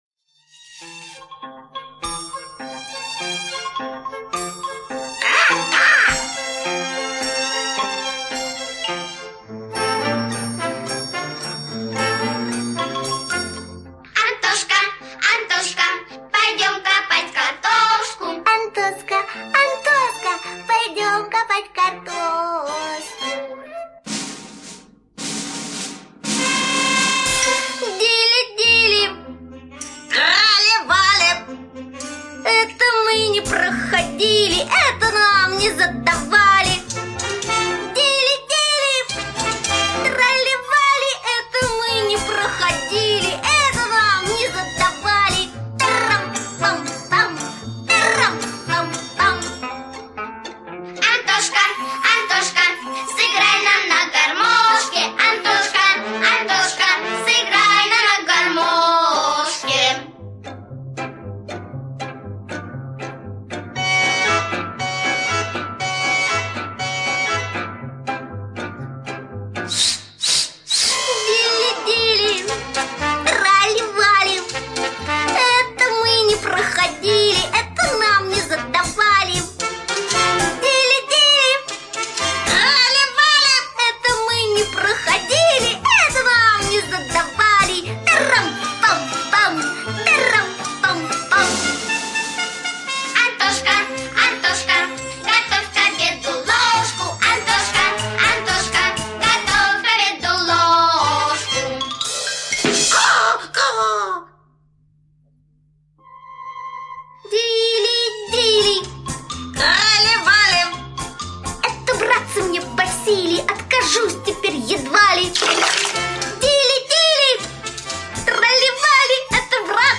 веселая песенка-частушка